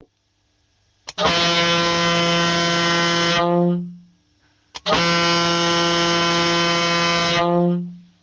Fisa luchthoorn Gigant 70 4-8,5 bar | 146192
Fisa luchthoorn Gigant 70 4-8,5 bar118dB197Hz high tone182Hz low tone